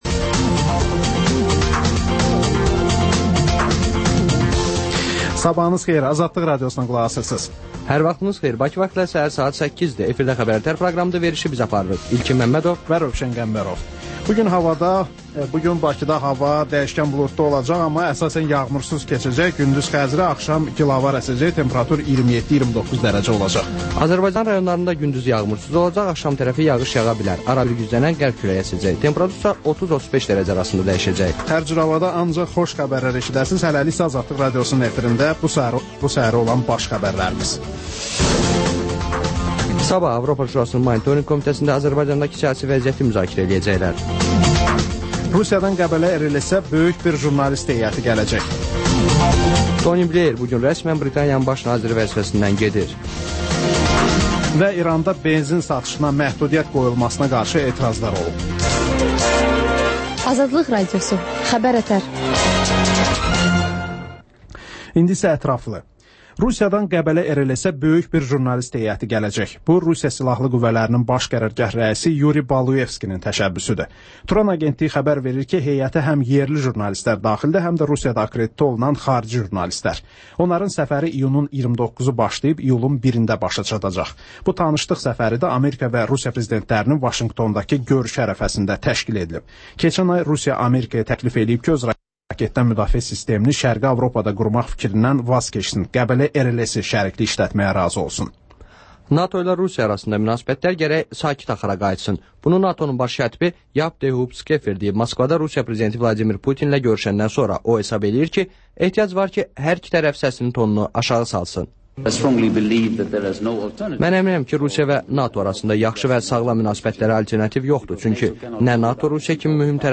Xəbər-ətər: xəbərlər, müsahibələr və ŞƏFFAFLIQ: Korrupsiya haqqında xüsusi veriliş